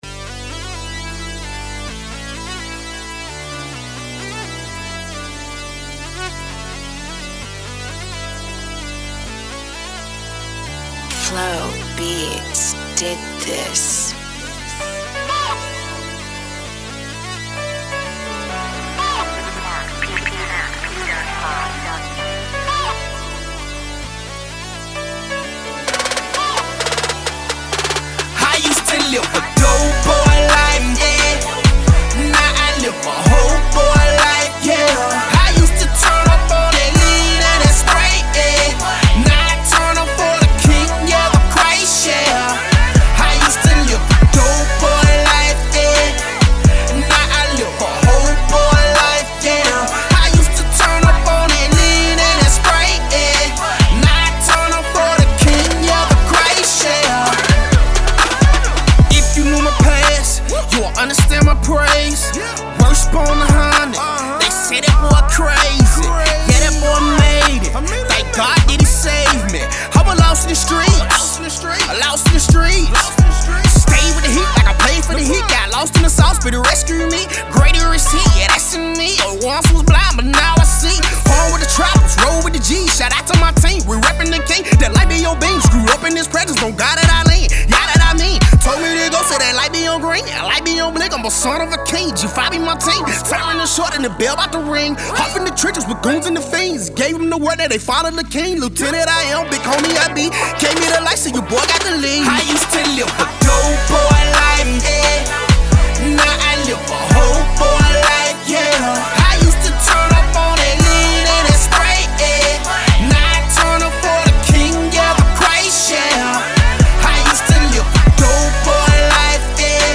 Brand new music from up and coming Christian Artists.